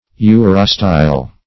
urostyle - definition of urostyle - synonyms, pronunciation, spelling from Free Dictionary
Search Result for " urostyle" : The Collaborative International Dictionary of English v.0.48: Urostyle \U"ro*style\, n. [2d uro- + Gr.